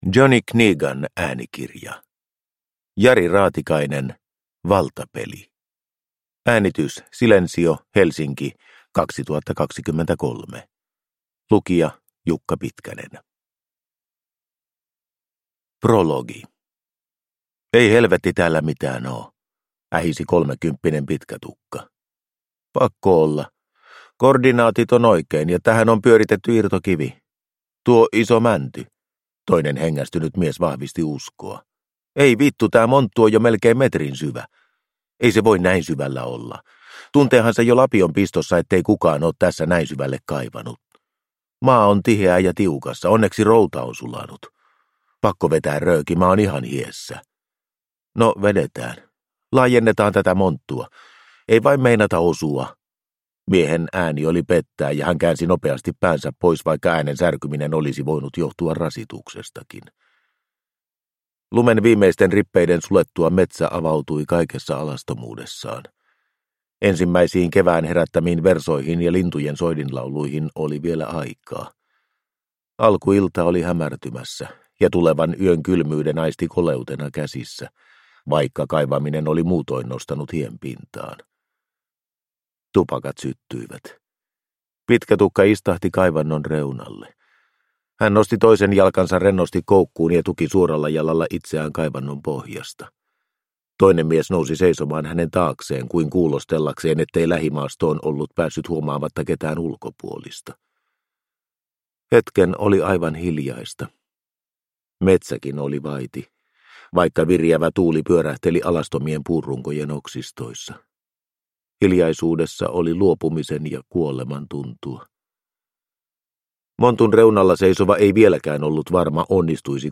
Valtapeli – Ljudbok – Laddas ner
Uppläsare: